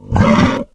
boar_pain_3.ogg